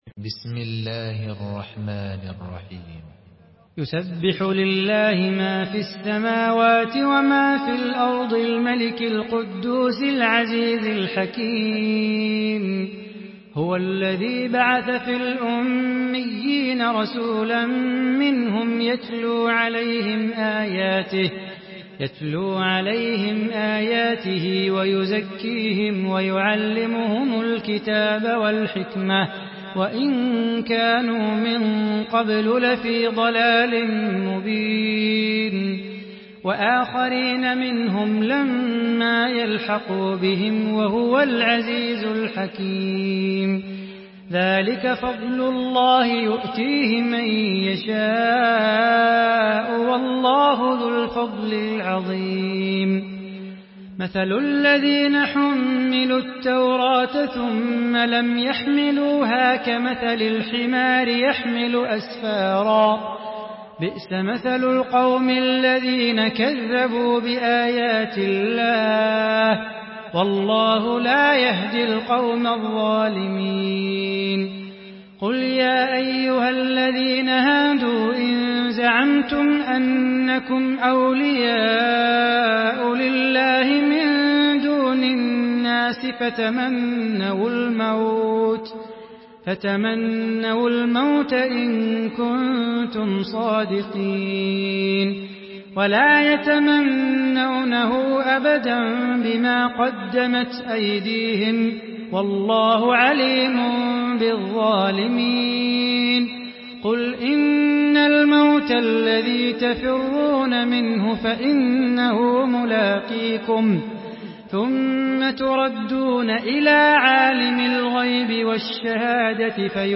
Surah Cüma MP3 by Salah Bukhatir in Hafs An Asim narration.
Murattal Hafs An Asim